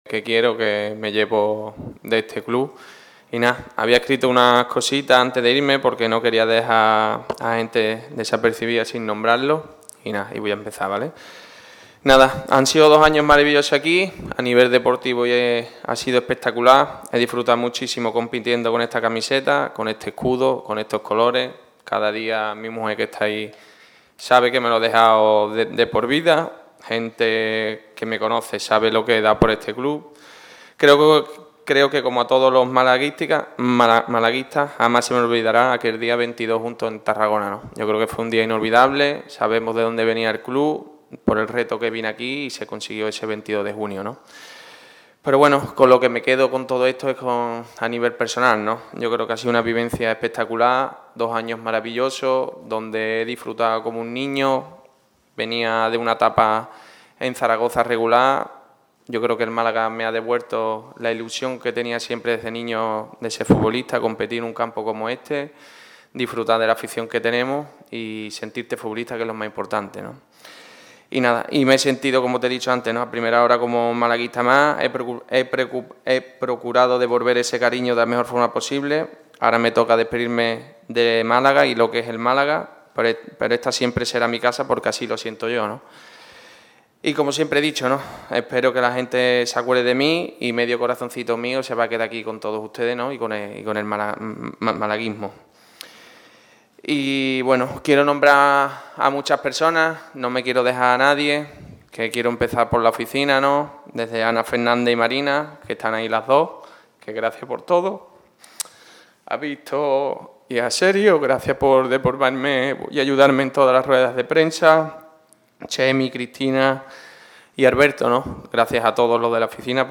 Repasa aquí la última rueda de prensa de Manu Molina como jugador del Málaga CF.
Con un rostro visiblemente emocionado y acompañado por su familia, Manu Molina ha comparecido ante los medios tras el anuncio por parte del club de su no continuidad en la plantilla malaguista.